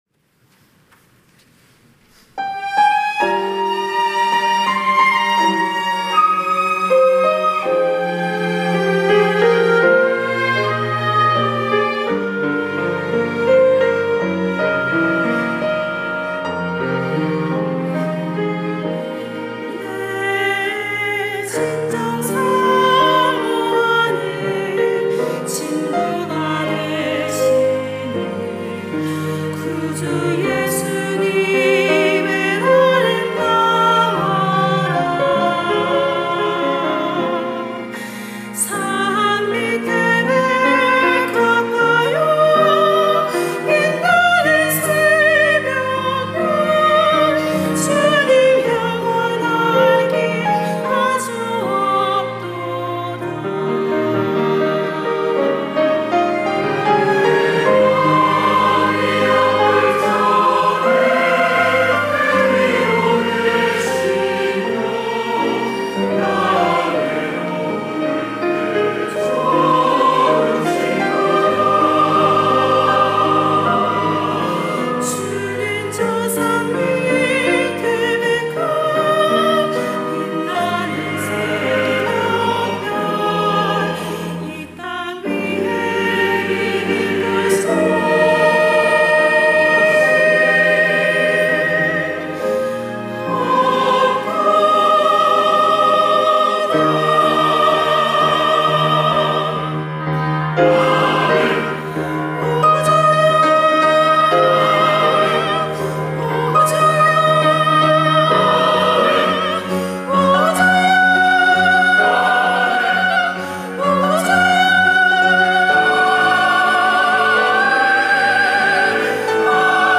할렐루야(주일2부) - 내 진정 사모하는
찬양대